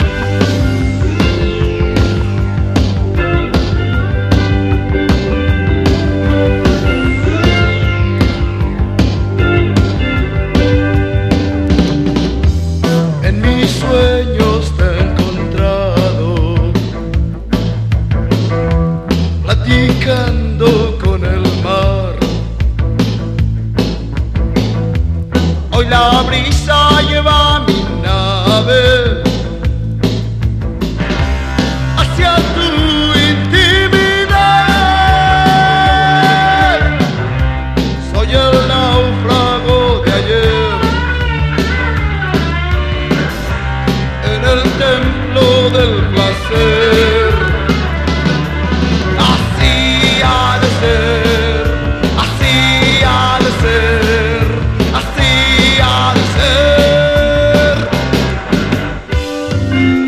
EASY LISTENING / EASY LISTENING / LATIN
メキシコ産インスト・コンボ！